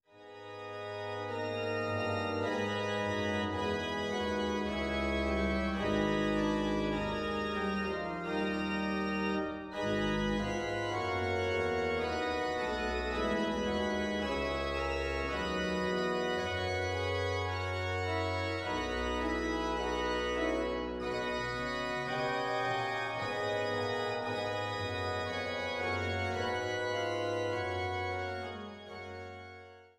Bad Lausick